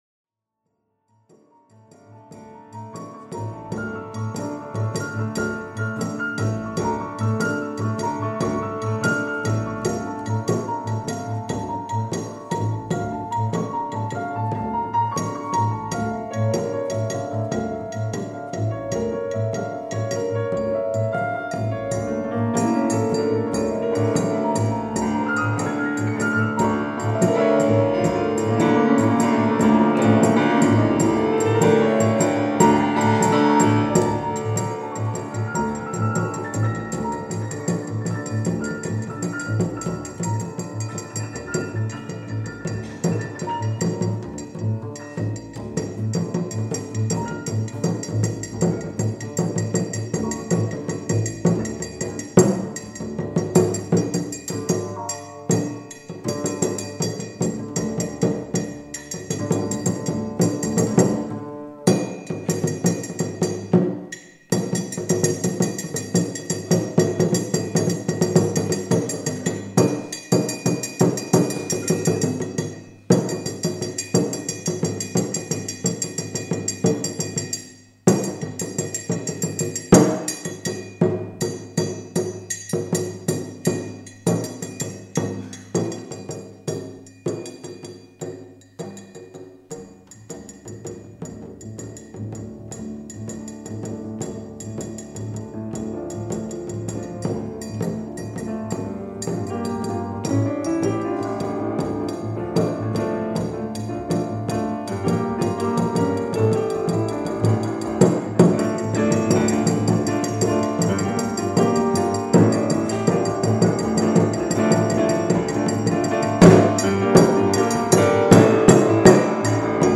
Salle Cortot (Sala Cortot), (XVII Distrito de París)
a las percusiones africanas
al contrabajo
relacionando la música klezmer con la música africana